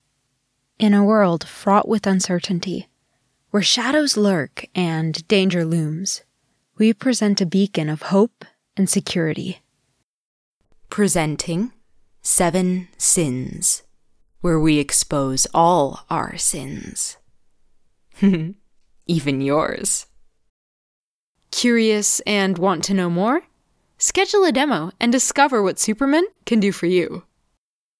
Mini_Demo_Narration.mp3